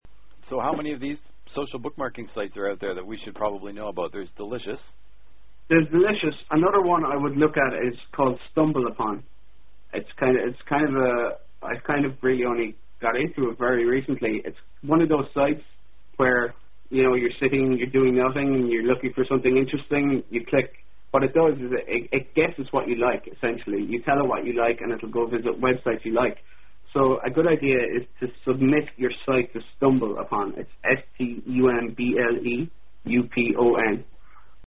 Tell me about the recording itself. The following excerpts were taken directly from the TeleClass.